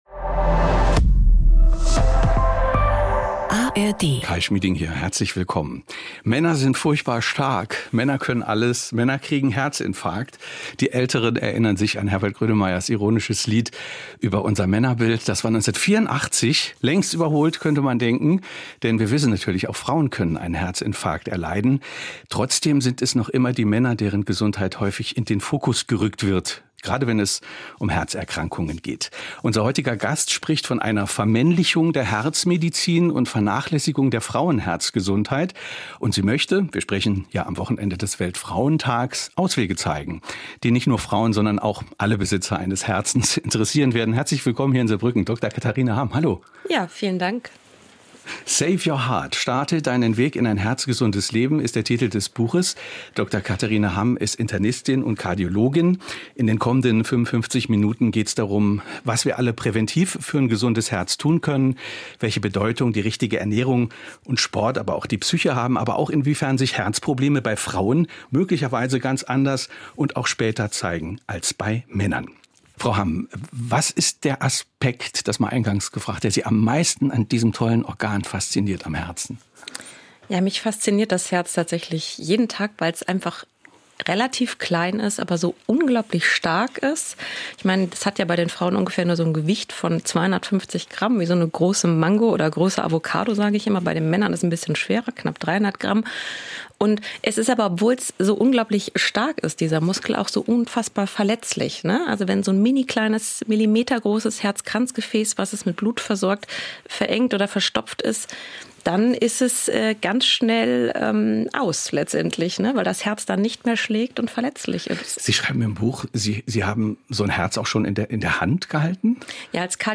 Die traditionsreichste Sachbuchsendung im deutschen Sprachraum stellt seit über 50 Jahren jeweils ein Buch eines Autors eine Stunde lang im Gespräch vor. Die Themen reichen von Politik und Wirtschaft bis zu Gesundheit, Erziehung oder Psychologie.